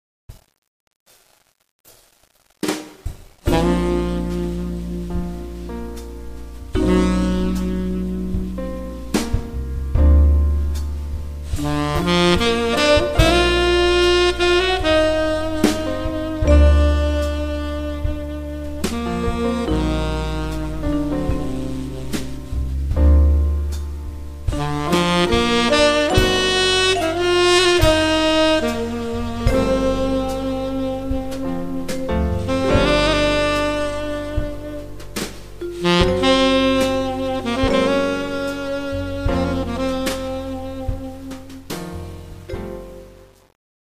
Recorded at Sanctuary Studios, Broadalbin, NY 2004-2011